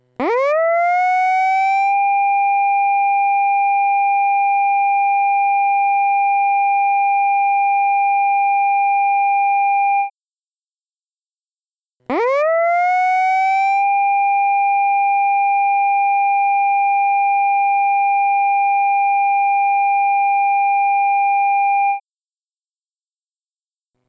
サイレン（10秒吹鳴2秒休止×2回）
(注意)試聴の際は、音量に御注意ください